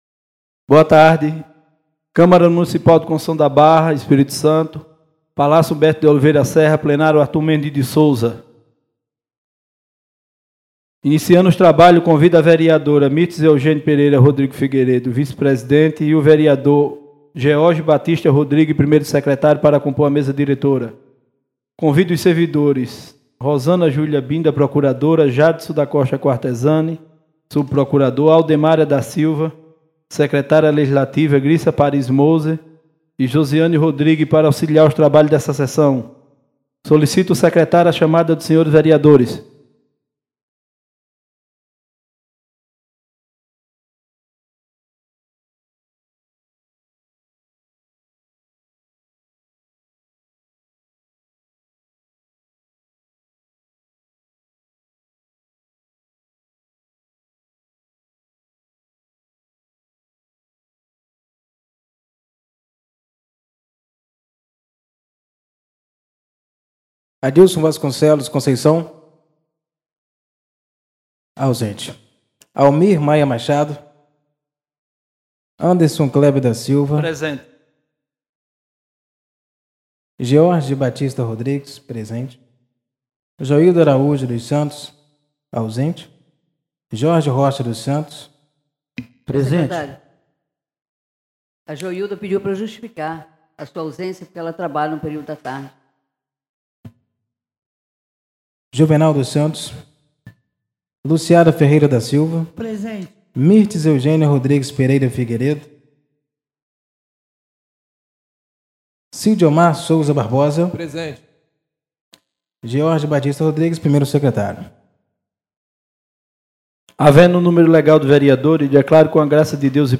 2ª Sessão Extraordinária do dia 16 de março de 2020 — Câmara de Conceição da Barra